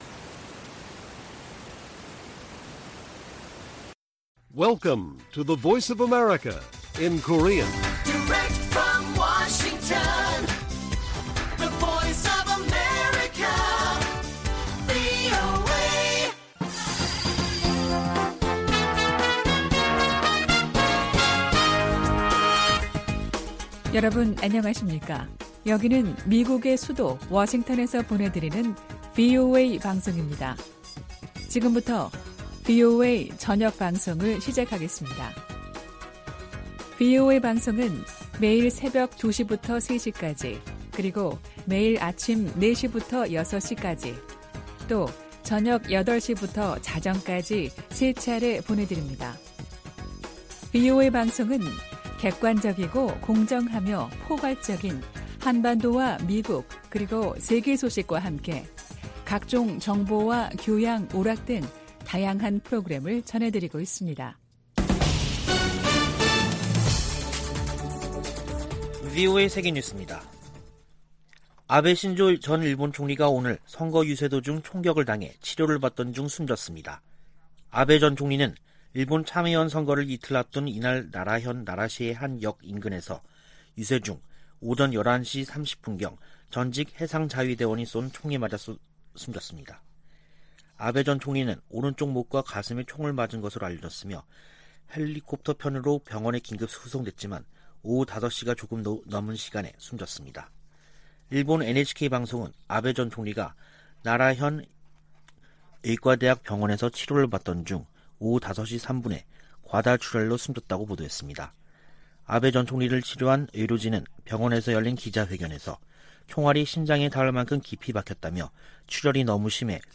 VOA 한국어 간판 뉴스 프로그램 '뉴스 투데이', 2022년 7월 8일 1부 방송입니다. 미국과 한국, 일본이 G20 외교장관회의를 계기로 3국 외교장관 회담을 열고 북한 문제 등을 논의했습니다. 미의회에서는 북핵 문제를 넘어 미한일 공조를 강화하려는 움직임이 두드러지고 있습니다.